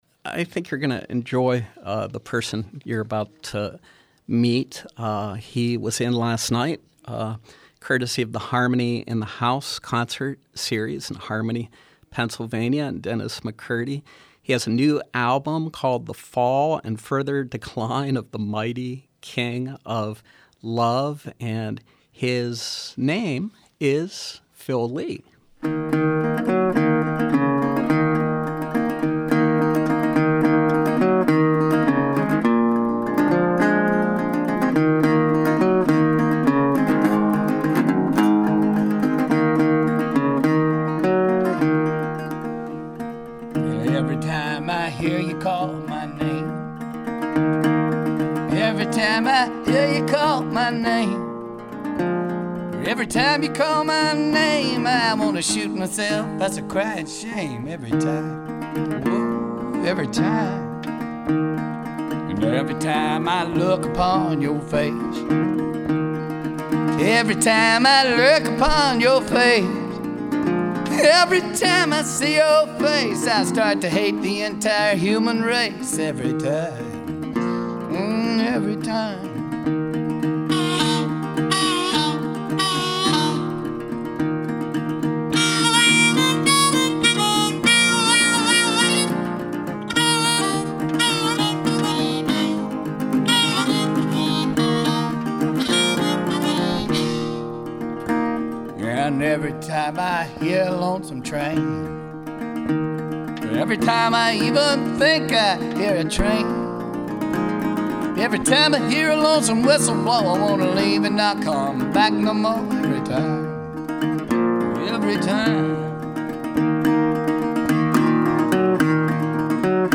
left-of-center troubadour